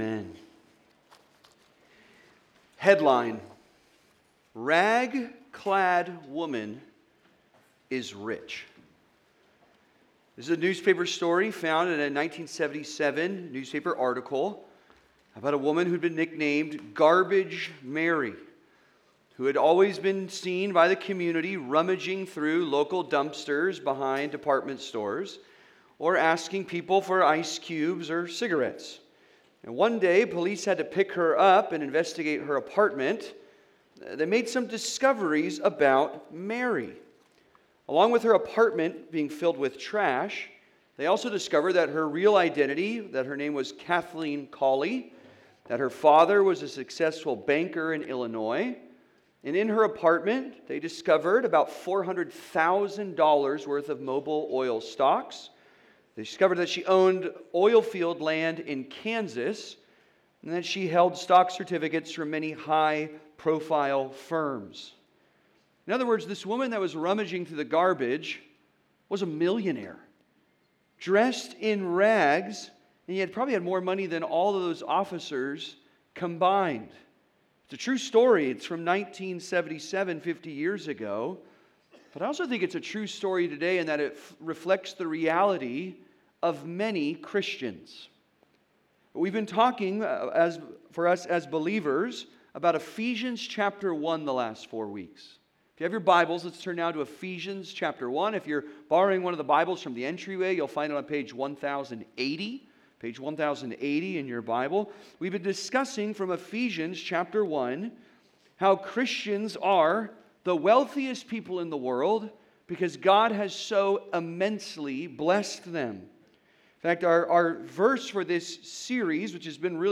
Sealed by The Spirit (Sermon) - Compass Bible Church Long Beach